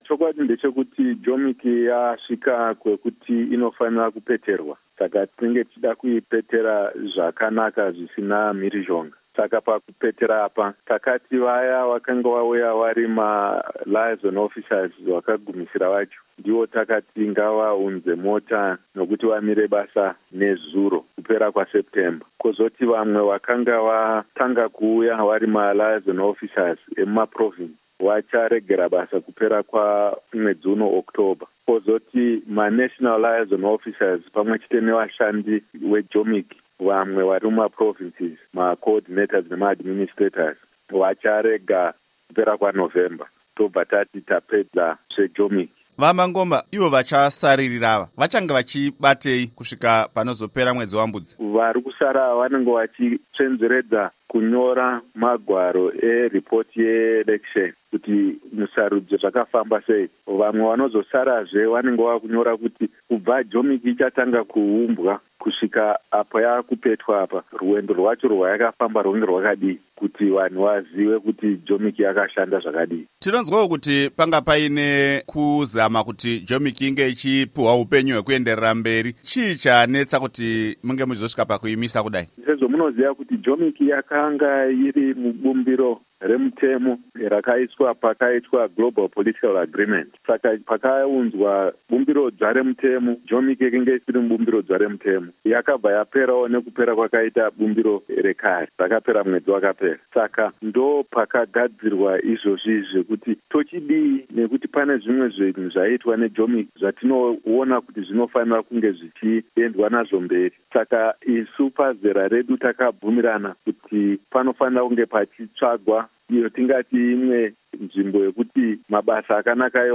Hurukuro naVaElton Mangoma